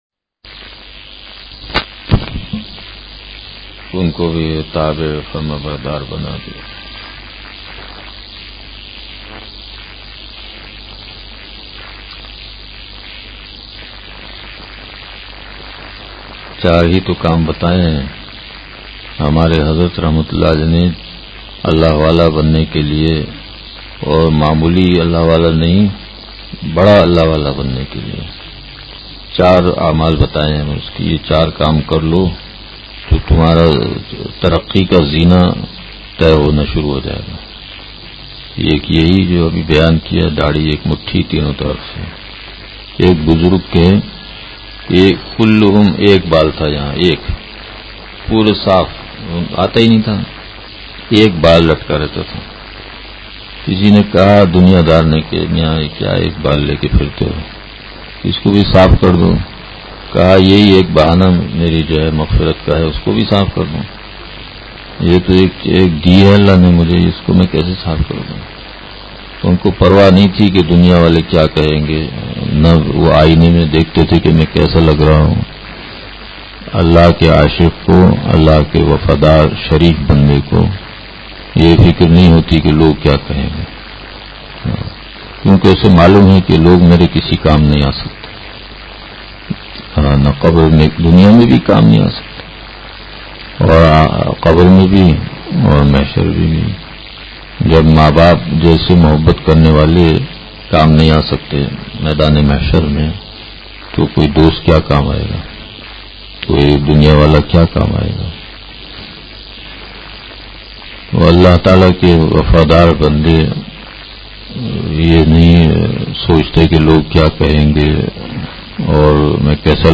بیان – خزائن الحدیث – نشر الطیب فی ذکر النبی الحبیب صلی اللہ علیہ وسلم